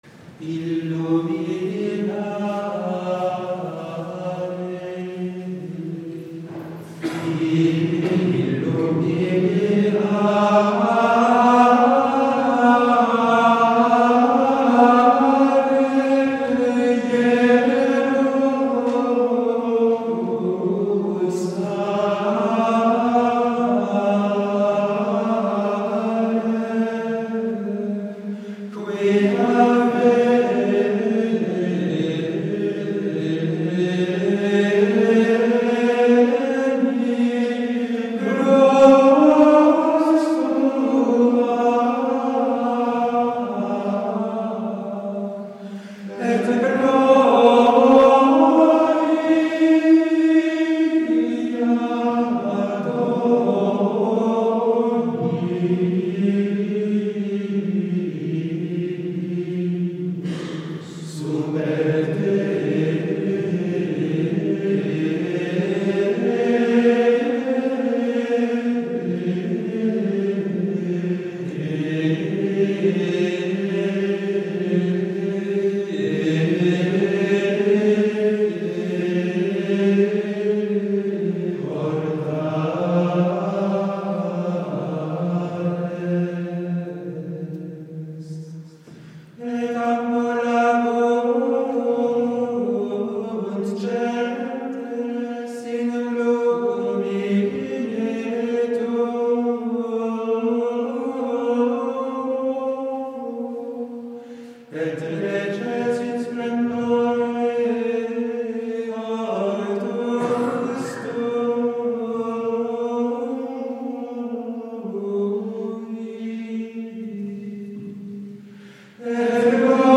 Please consider this recording of the responsory Illumináre, Illumináre Jerúsalem by the monks of Barroux Abbey.